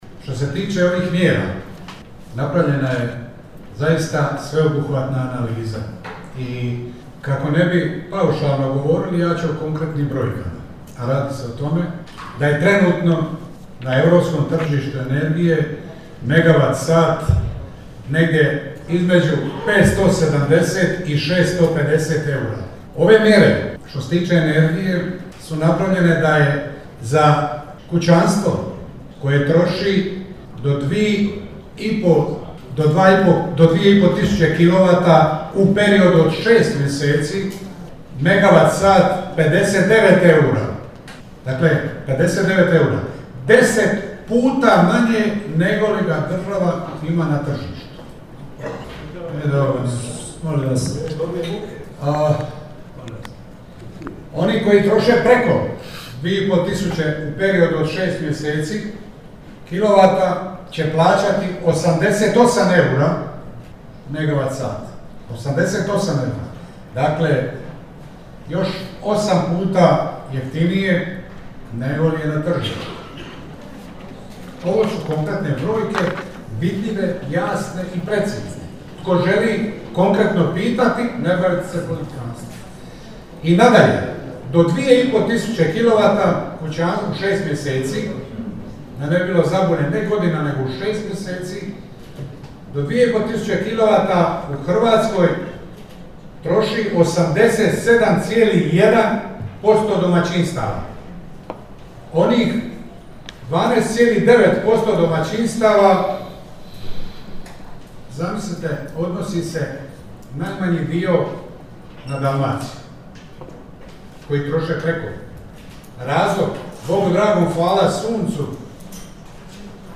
U Splitu je, u dvorani Kemijsko-tehnološkog fakulteta, u tijeku 12. sjednica Županijske skupštine Splitsko-dalmatinske županije.
Na prvo pitanje Mira Bulja, vezano za donesene mjere Vlade RH, odgovorio je župan Blaženko Boban ističući da su pitanja politikantska te potom kazao: